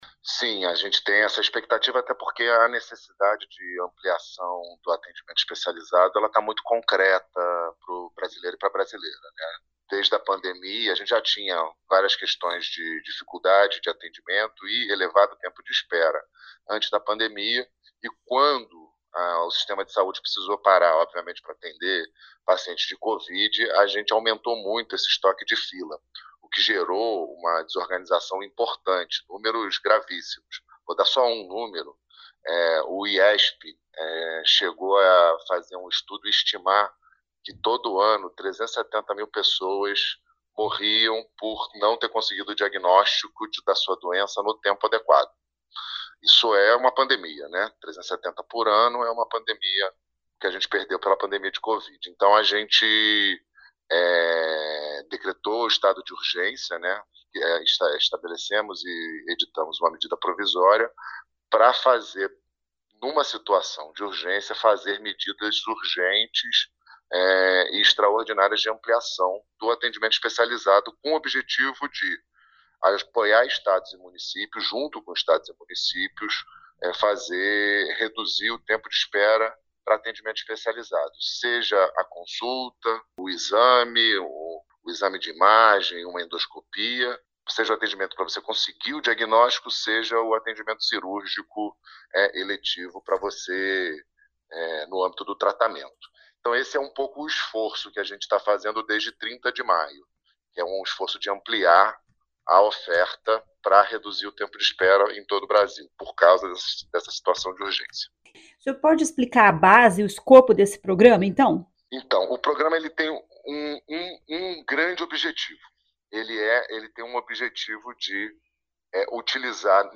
Ouça o que diz o representante do Ministério da Saúde, Rodrigo Oliveira.